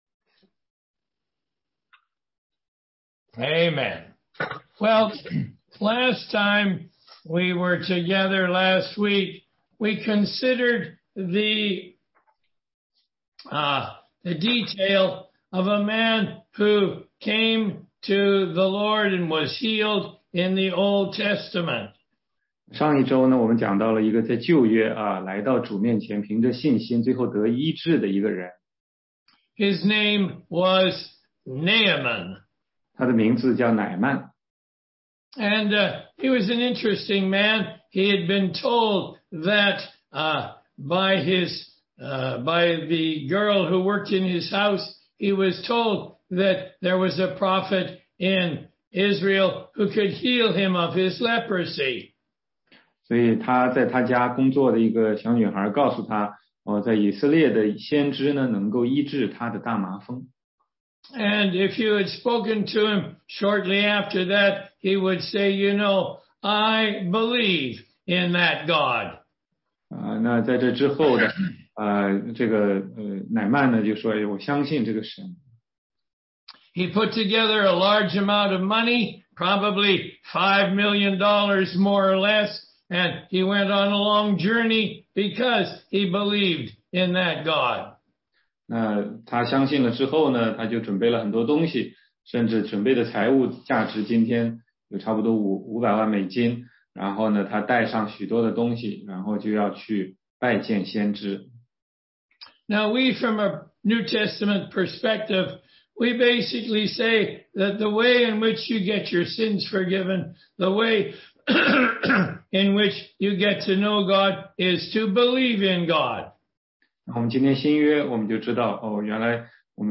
16街讲道录音 - 福音基础